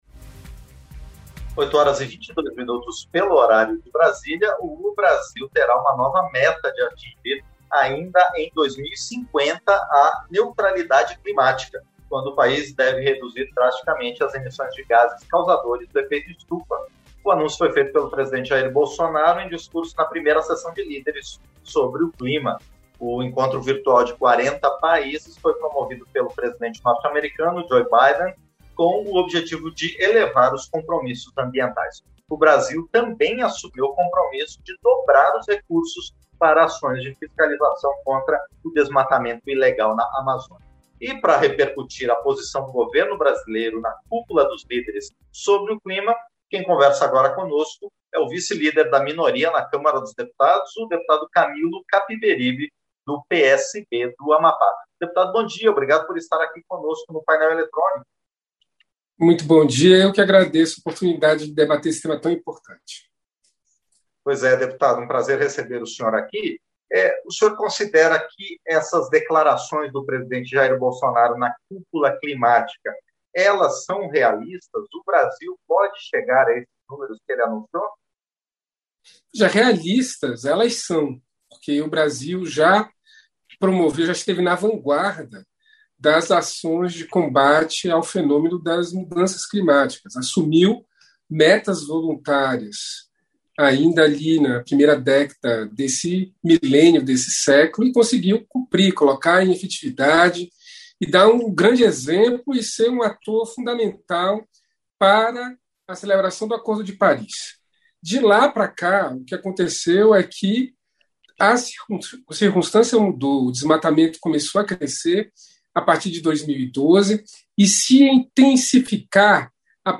Entrevista - Dep. Camilo Capiberibe (PSB-AP)